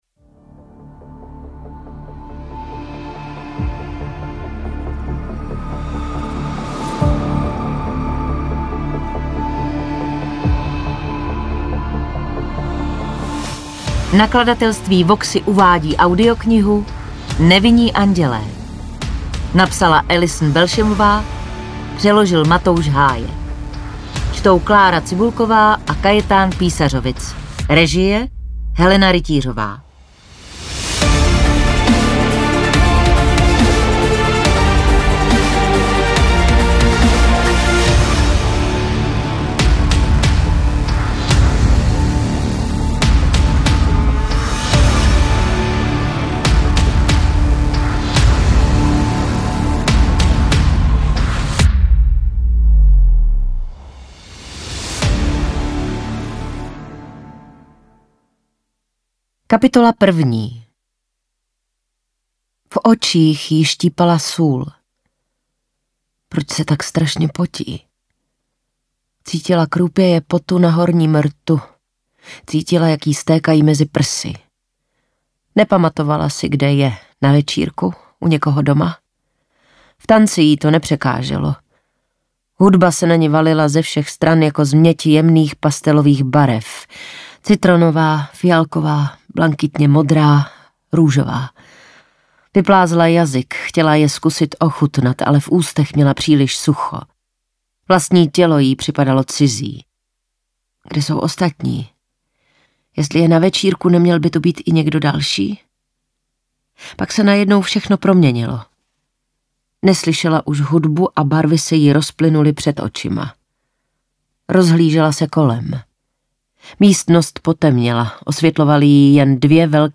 Audiobook
Read: Klára Cibulková